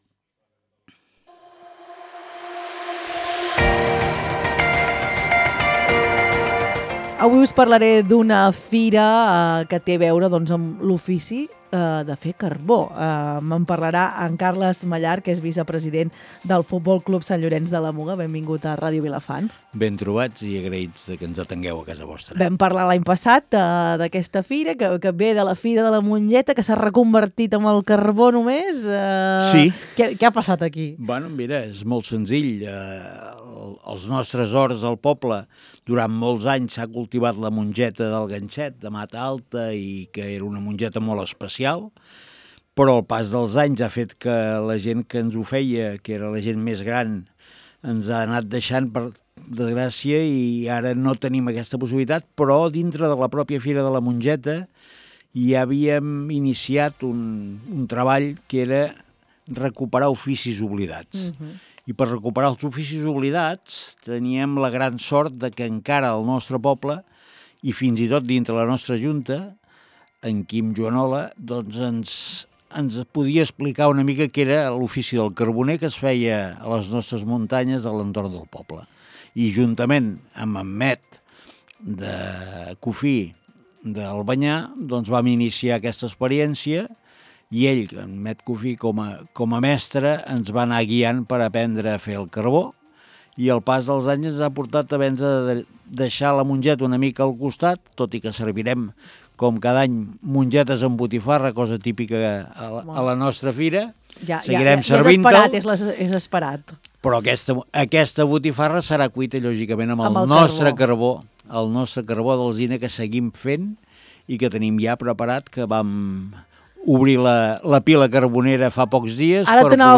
LVDM - ENTREVISTA - FIRA DEL CARBO SANT LLORENÇ 25 FEBRER 25~0.mp3